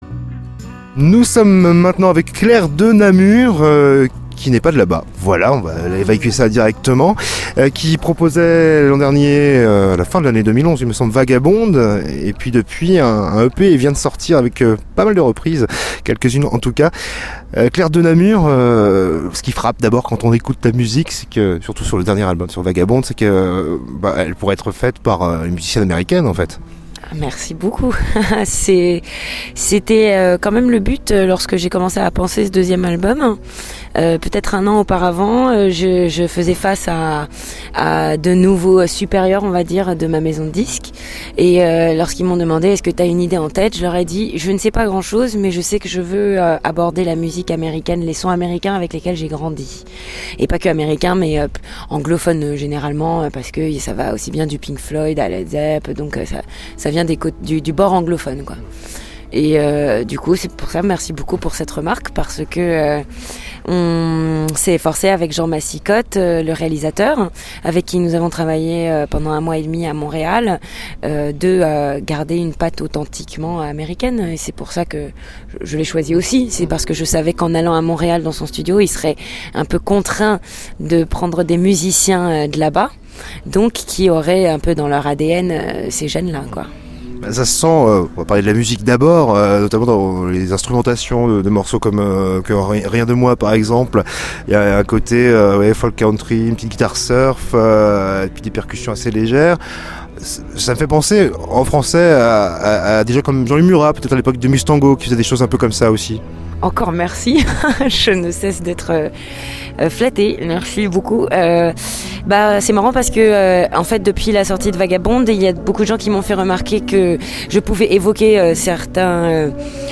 Interview CanalB En direct du festival Mythos (rennes) 5 Avril 2012